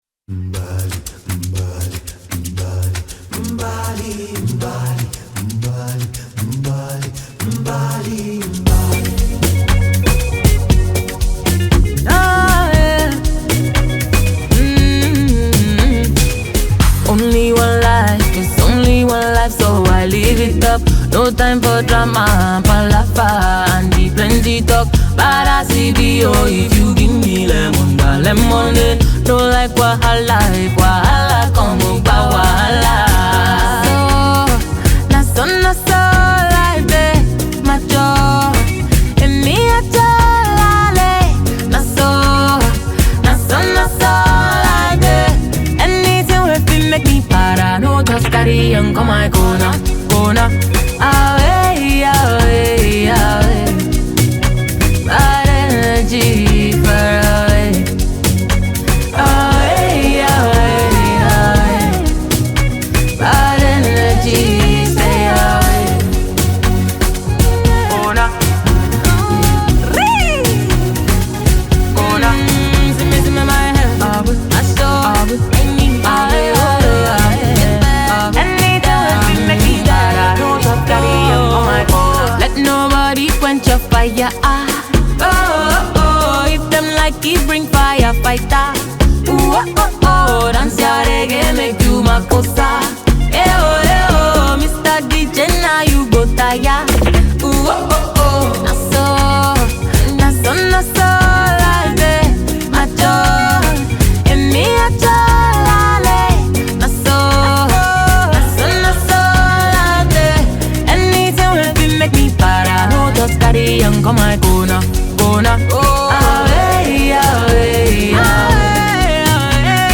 Nigerian songstress